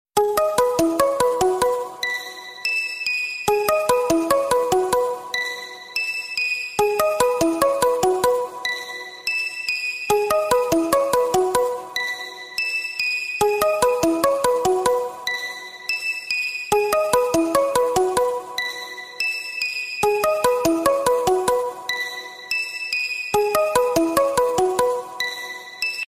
S24 Ultra default ringtone